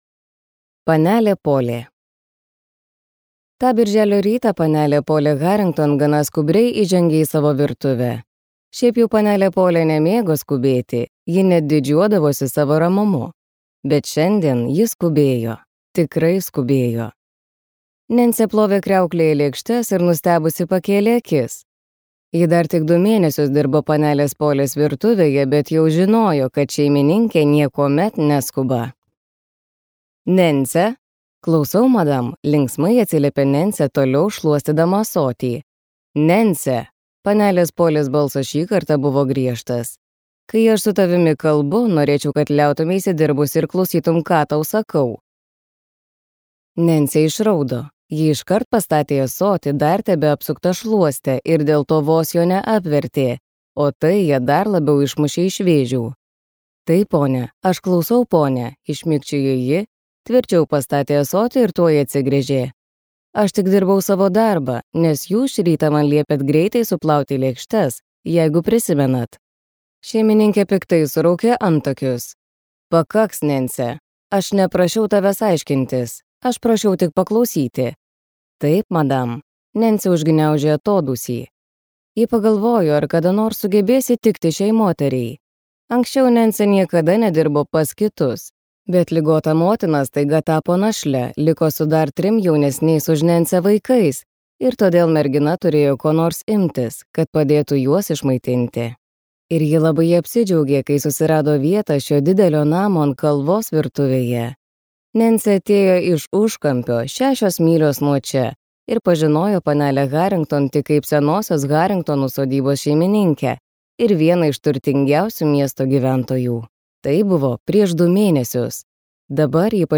Poliana | Audioknygos | baltos lankos